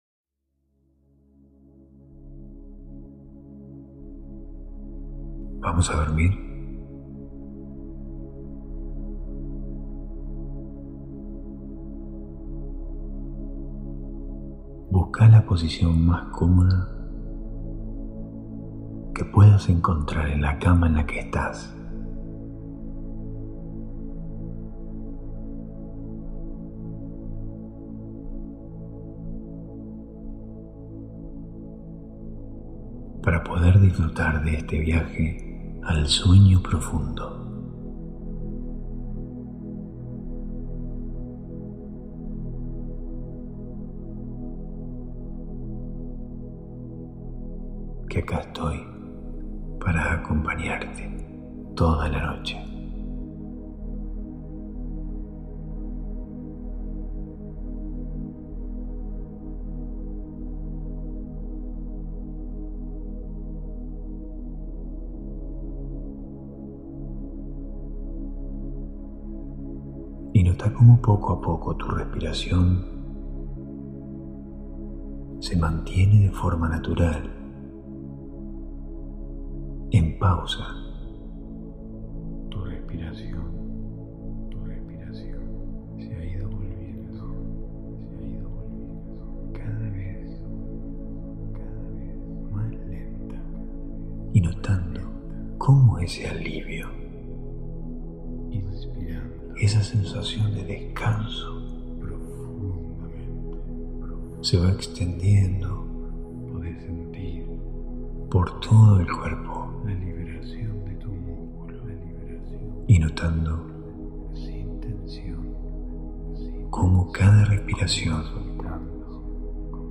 Hipnosis guiada para dormir. [Altamente recomendable escucharlo con auriculares ] Hosted on Acast.